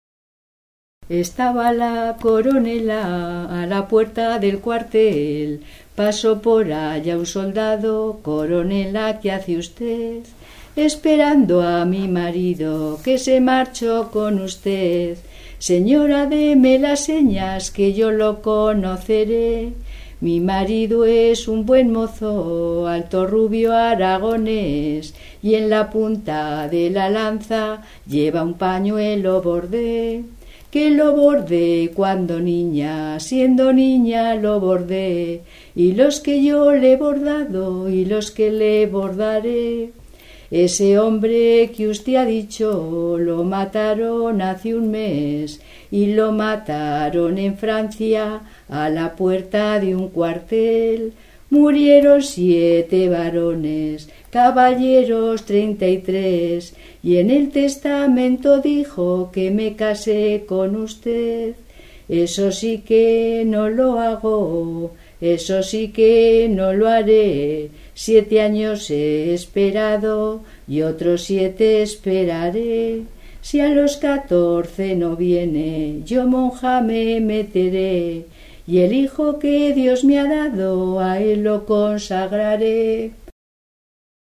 Clasificación: Romancero
Lugar y fecha de recogida: Badarán, 8 de marzo de 2012